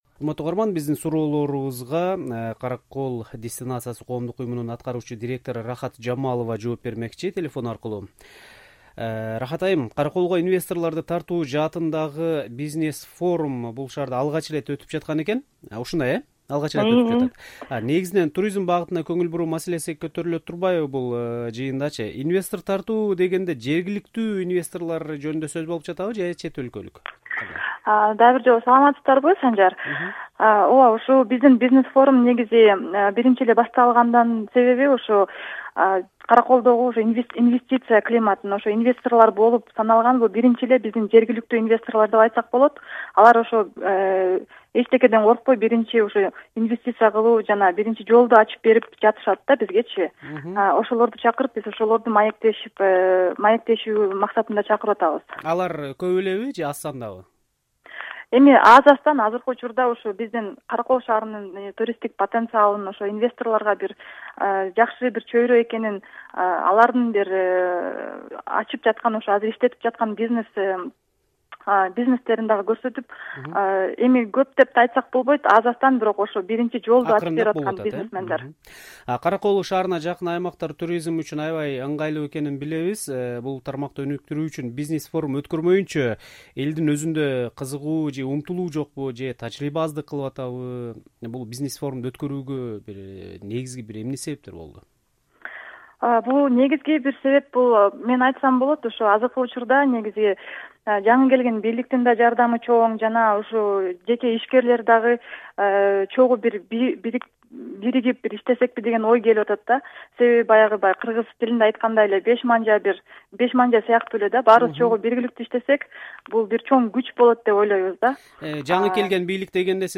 “Азаттык” радиосуна маек курду.